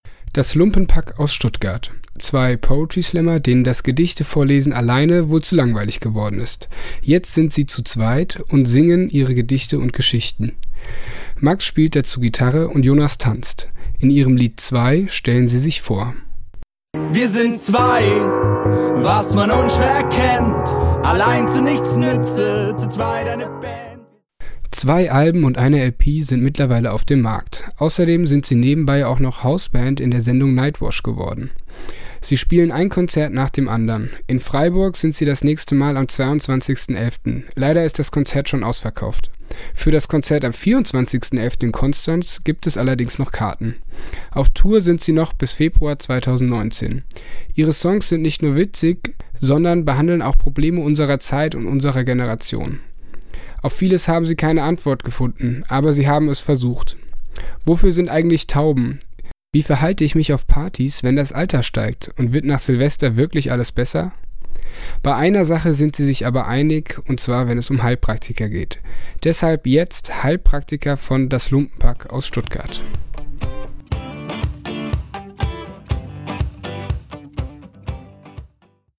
— MUSIKPASSAGEN AUS GEMA-RECHTLICHEN GRÜNDEN GEKÜRZT —